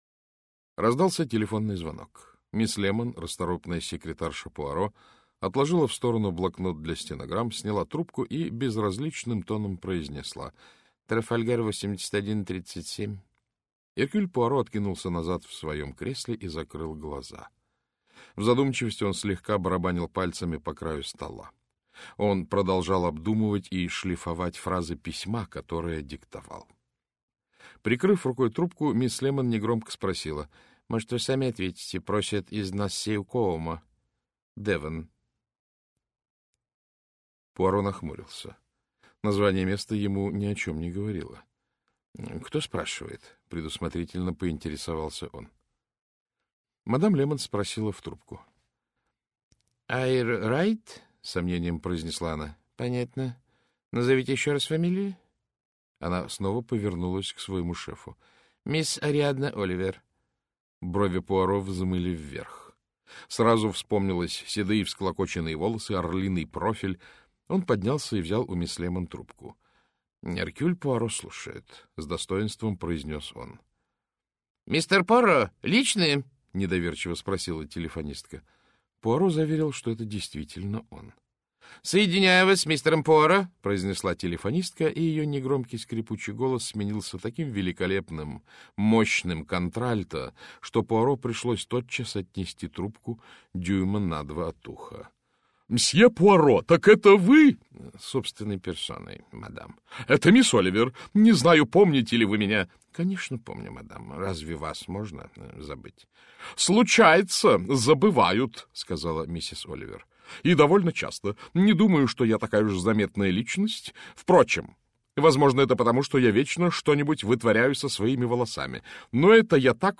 Аудиокнига Причуда мертвеца - купить, скачать и слушать онлайн | КнигоПоиск